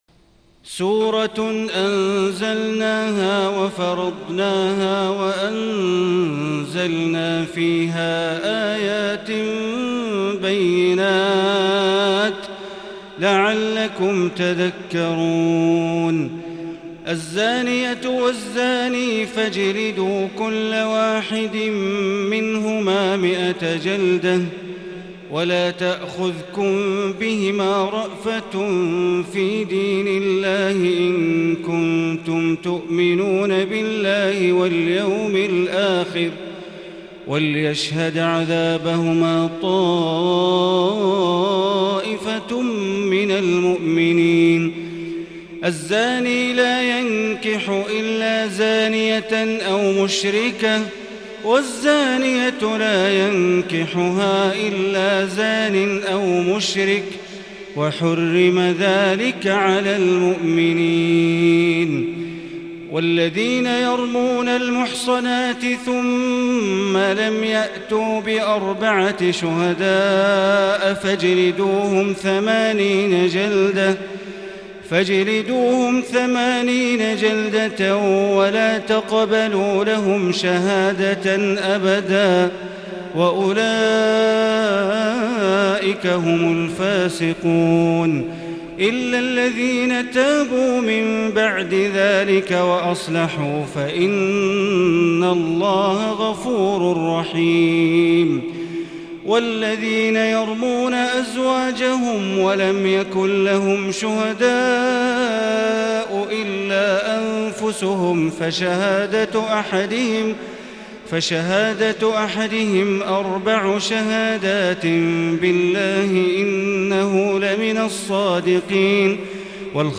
تهجد ليلة 22 رمضان 1441هـ | سورة النور كاملة | Tahajjud prayer | The night of Ramadan 22 1441H | Surah Nour > تراويح الحرم المكي عام 1441 🕋 > التراويح - تلاوات الحرمين